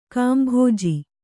♪ kāmbhōji